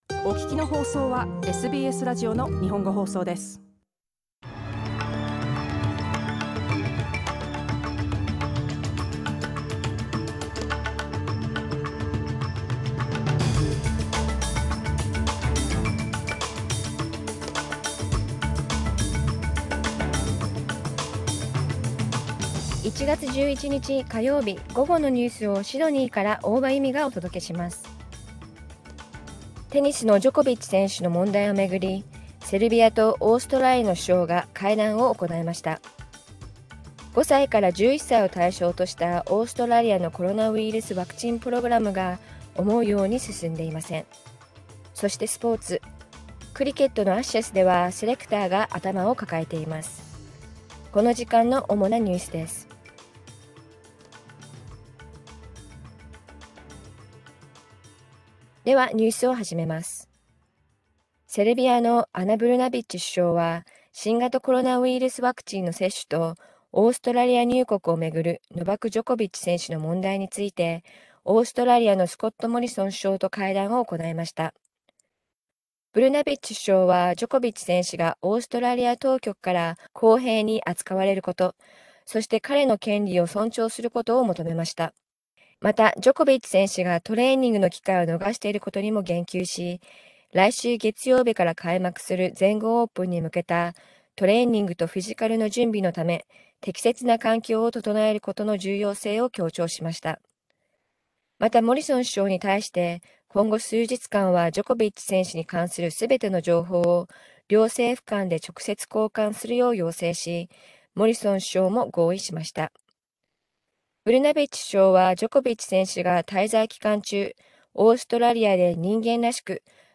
1月11日午後のニュース
Afternoon news in Japanese, 11 January 2022